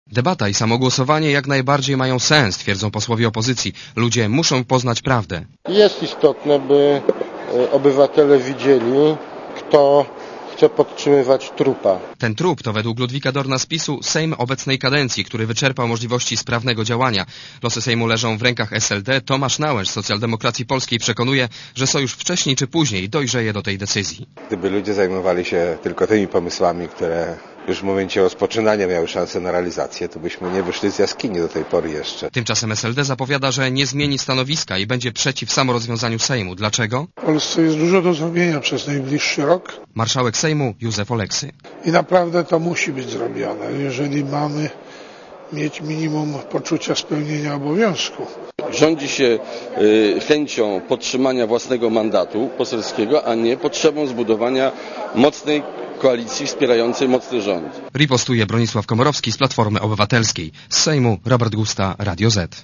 Źródło: RadioZet Relacja reportera Radia ZET Głosowanie w sprawie skrócenia kadencji Sejmu w piątek.